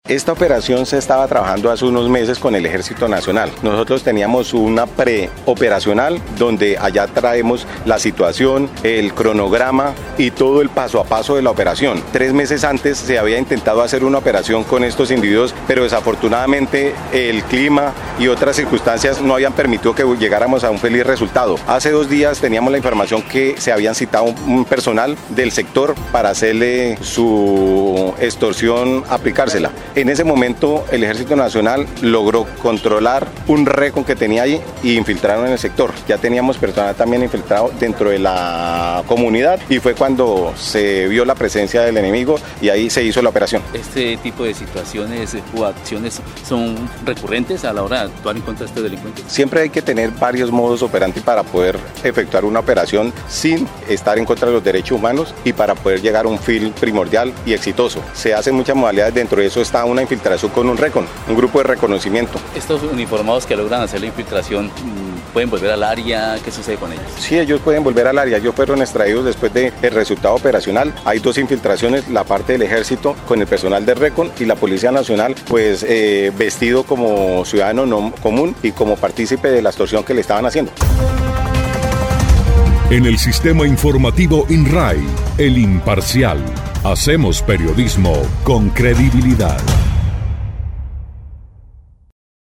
De acuerdo con el coronel Gosser Freddy Rangel, comandante encargado de la Policía Caquetá, fueron varias semanas de infiltración, incluso haciéndose pasar por víctimas de la extorsión, las cuales permitieron acabar con esa temida organización criminal.
03_CORONEL_GOSSER_RANGEL_OPERATIVO.mp3